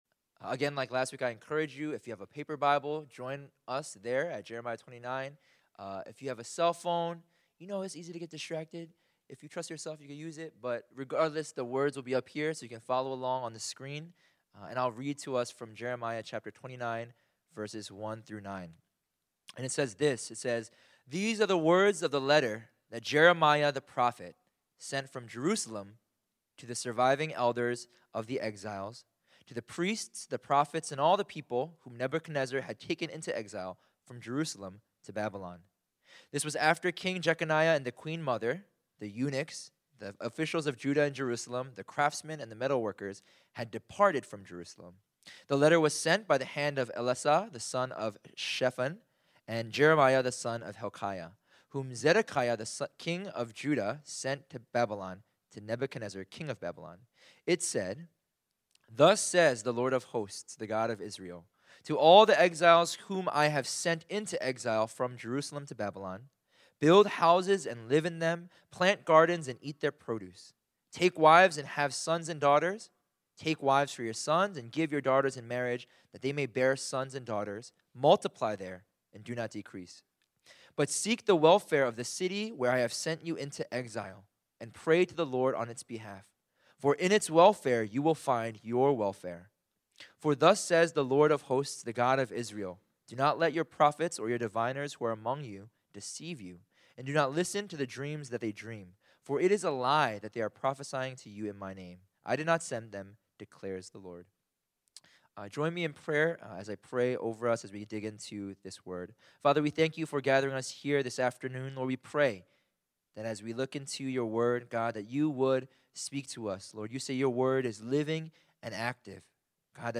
In this sermon, we look into the book of Jeremiah to discover God's heart for the city. Here we see that God cares for the city deeply and that he expects his people to also be concerned for the welfare and well-being of the city.